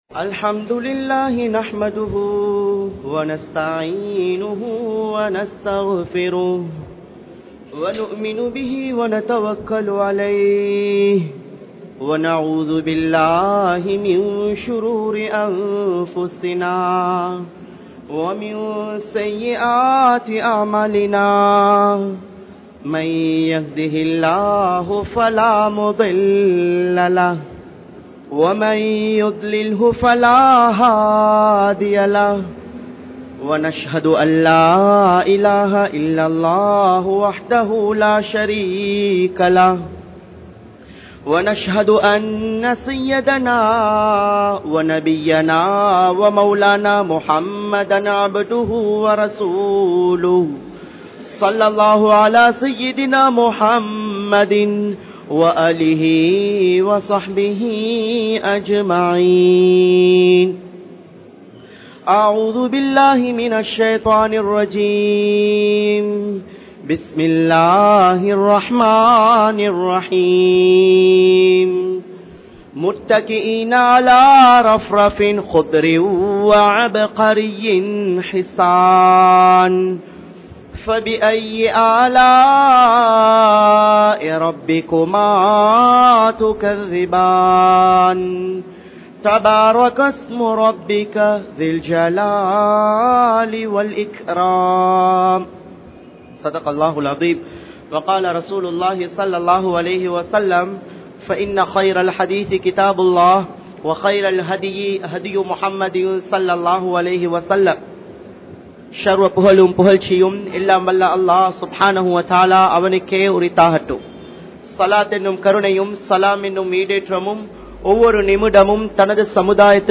Pettroarhalin Sirappu (பெற்றோர்களின் சிறப்பு) | Audio Bayans | All Ceylon Muslim Youth Community | Addalaichenai